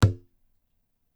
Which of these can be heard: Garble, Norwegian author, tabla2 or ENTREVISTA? tabla2